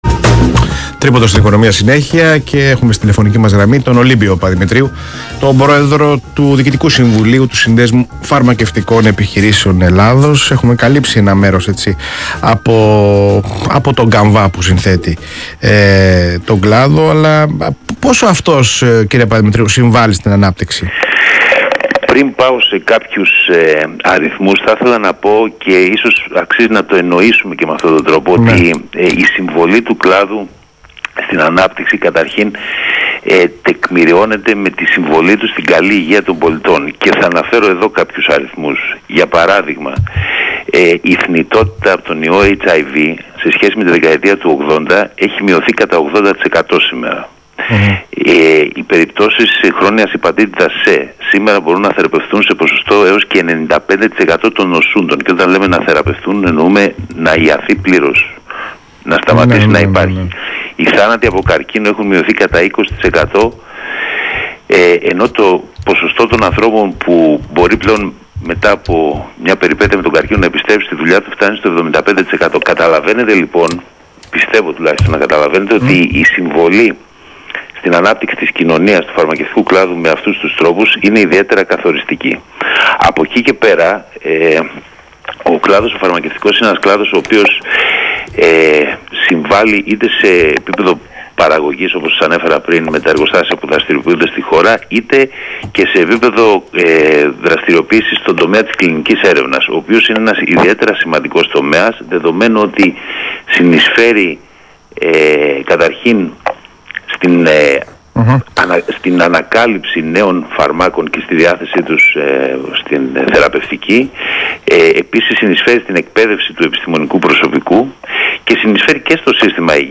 Ηχητικό απόσπασμα από την συνέντευξη